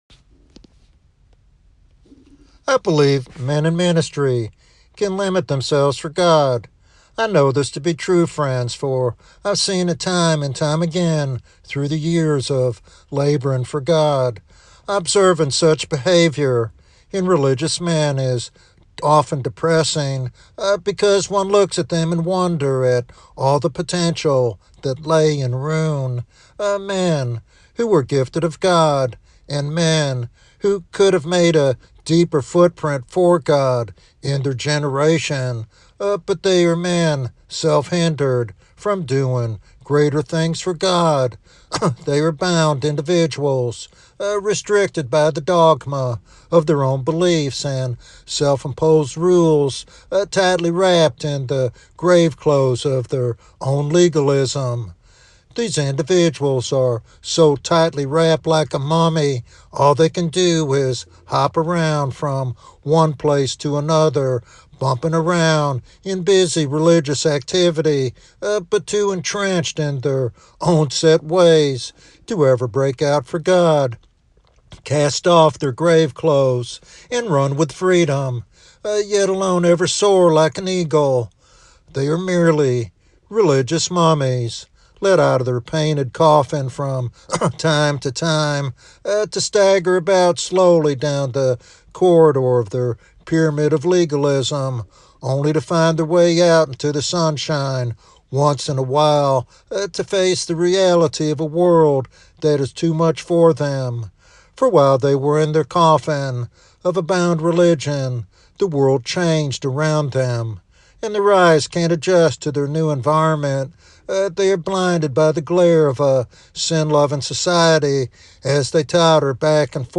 This sermon encourages Christians to cast off their grave clothes of legalism and embrace the freedom found in faith and obedience to Christ.